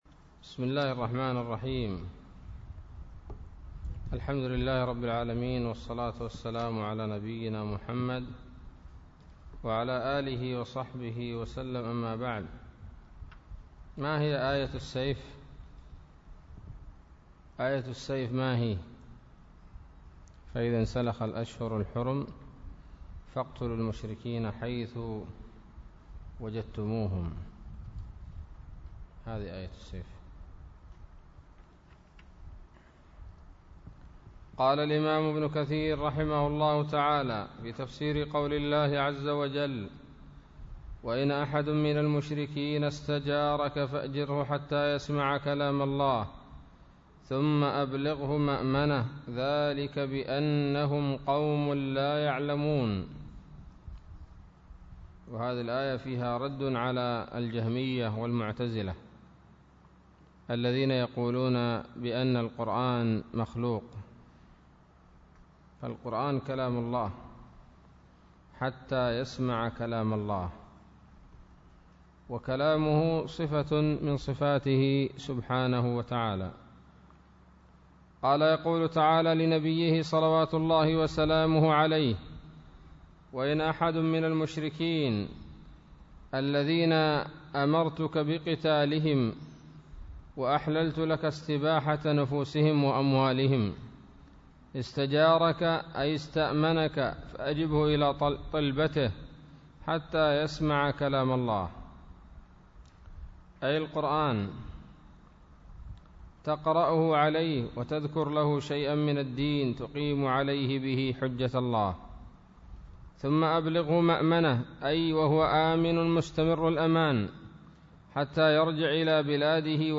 الدرس السادس من سورة التوبة من تفسير ابن كثير رحمه الله تعالى